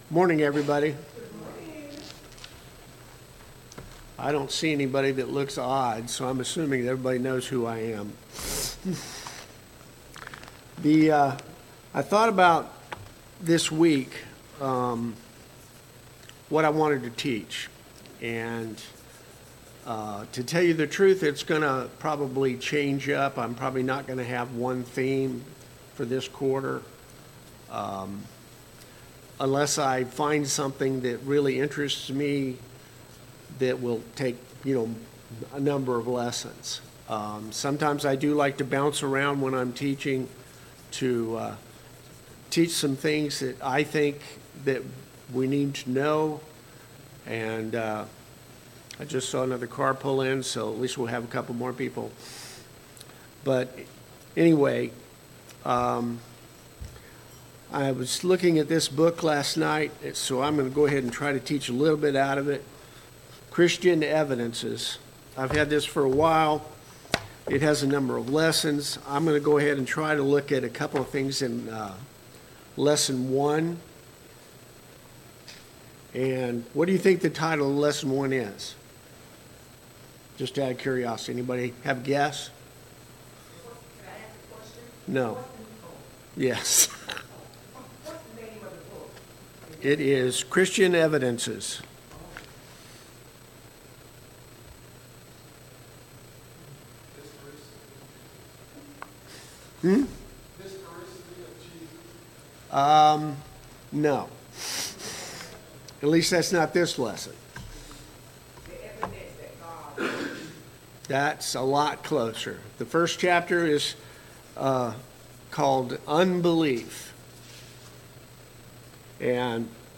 Service Type: Sunday Morning Bible Class